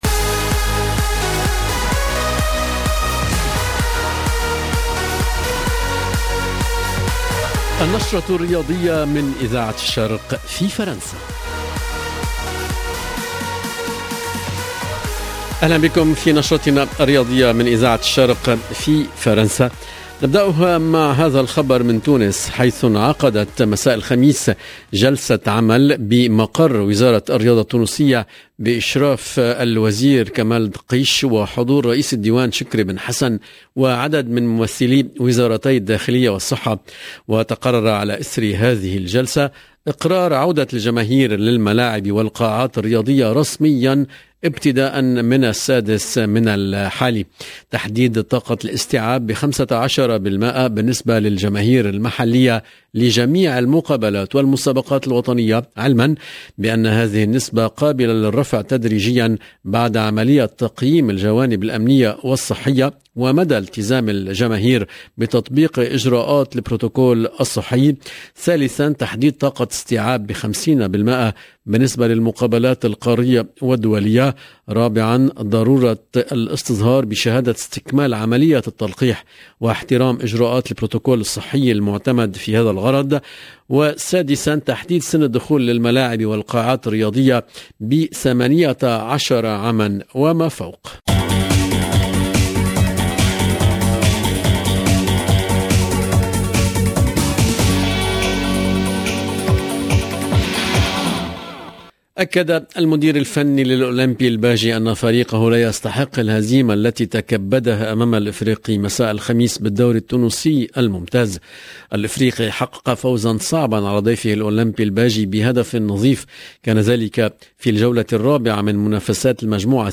FOOT ALGERIE MAROC TUNISIE 6 novembre 2021 - 10 min 43 sec RADIO ORIENT SPORT JS SPORT Dans notre journal du sport de vendredi nous ferons un tour sur les championnats Africains de football notamment en Algérie Tunisie et Maroc… 0:00 10 min 43 sec